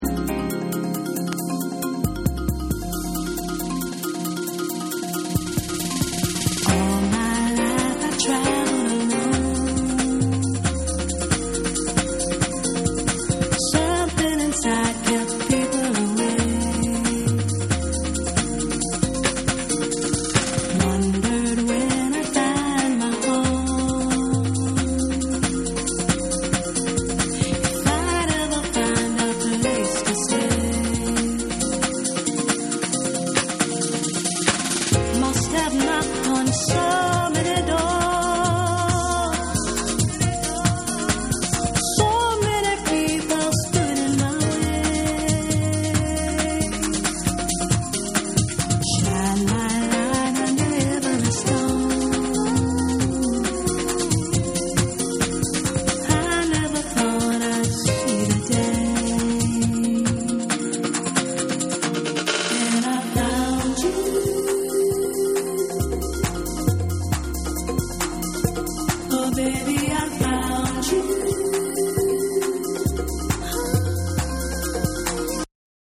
爽快なメロディーと歌声が映えるドラムンベースのリズムも取り入れた1。
TECHNO & HOUSE